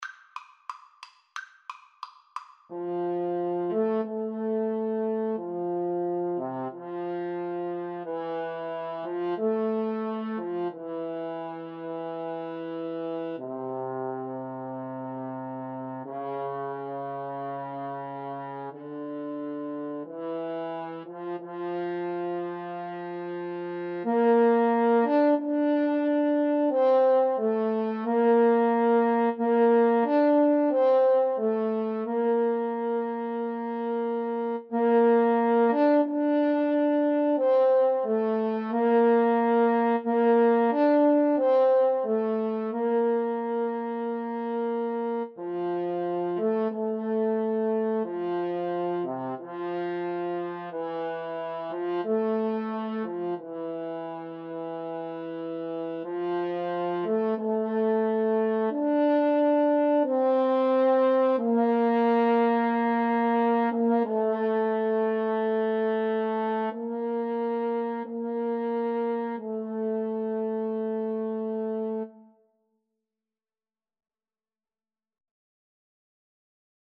F major (Sounding Pitch) C major (French Horn in F) (View more F major Music for French Horn Duet )
=180 Largo
French Horn Duet  (View more Easy French Horn Duet Music)
Classical (View more Classical French Horn Duet Music)